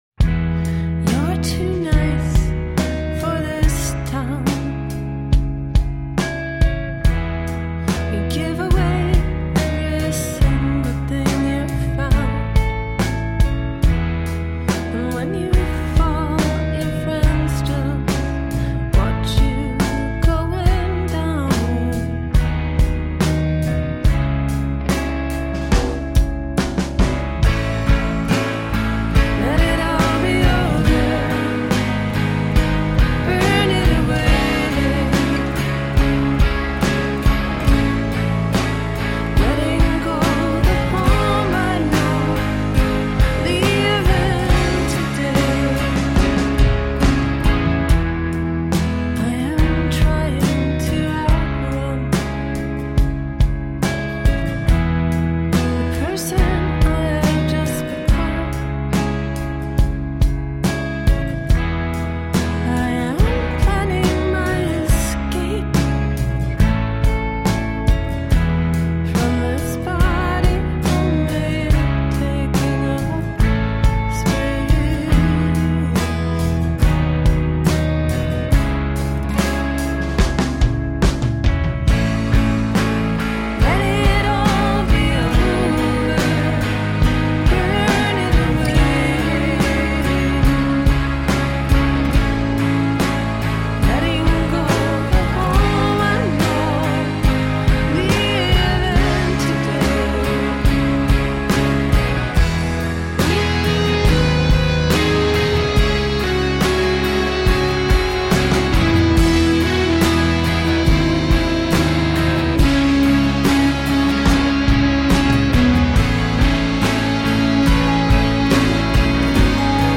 Dreamy, slightly mournful indie pop.
Tagged as: Electro Rock, Pop